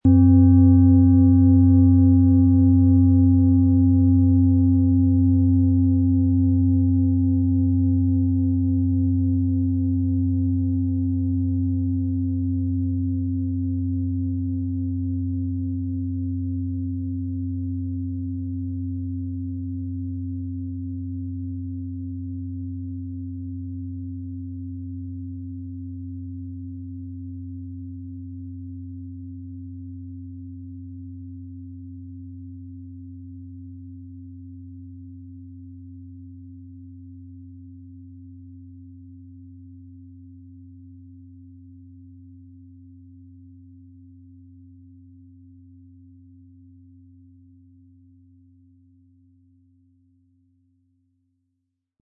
Diese tibetische Klangschale mit dem Ton von DNA wurde von Hand gearbeitet.
• Tiefster Ton: Jupiter
PlanetentöneDNA & Jupiter
MaterialBronze